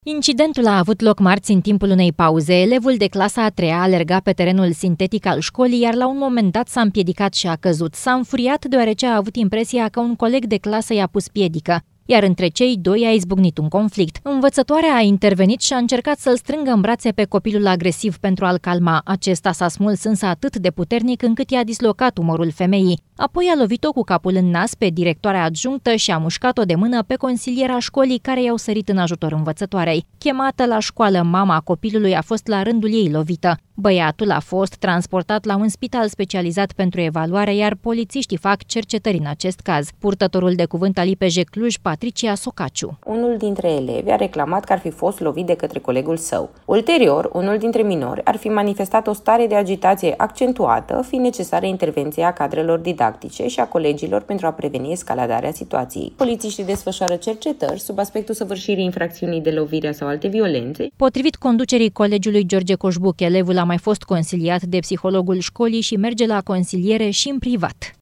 Știri > Social > Cluj: Un elev de 9 ani a dislocat umărul unei învățătoare și a agresat alte două cadre didactice.